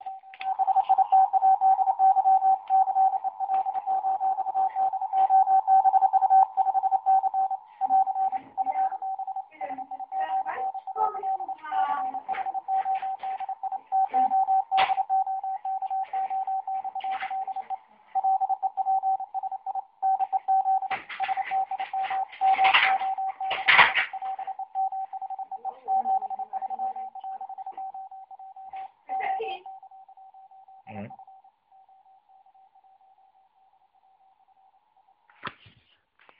Signál z ATS-3B?